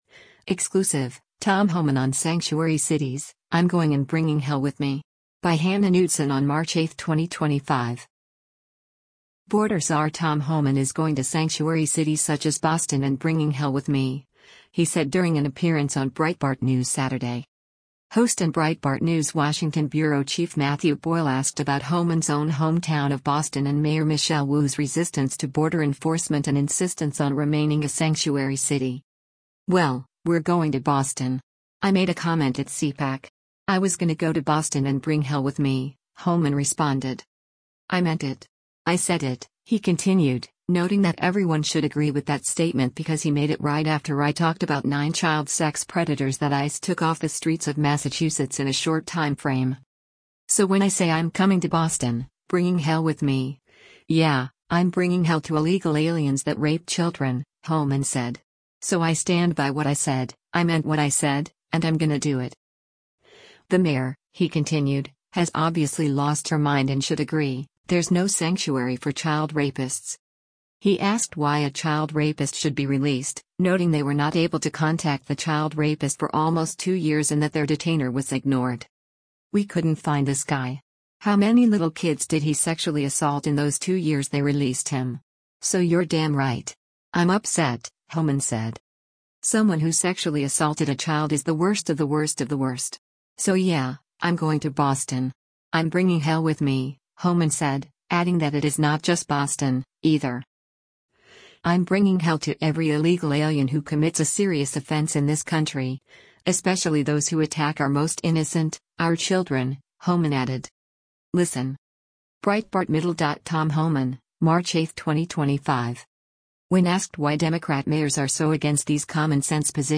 Border czar Tom Homan is going to sanctuary cities such as Boston and “bringing hell with me,” he said during an appearance on Breitbart News Saturday.